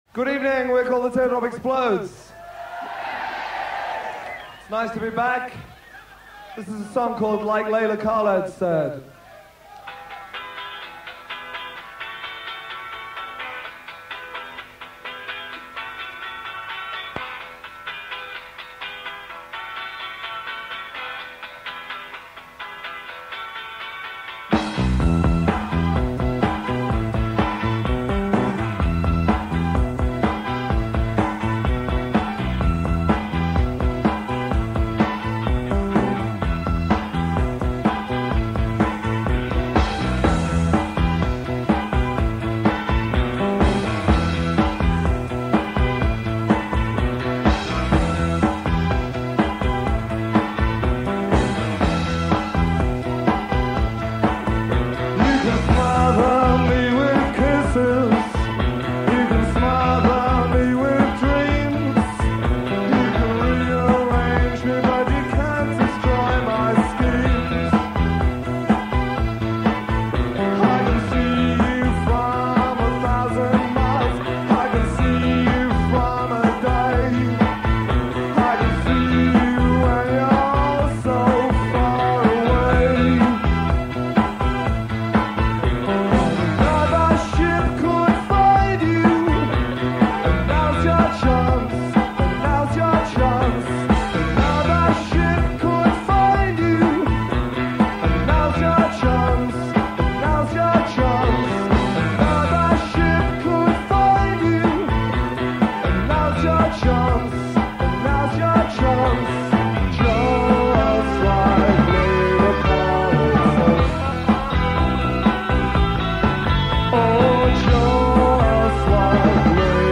recorded at Sheffield University
live at Sheffield University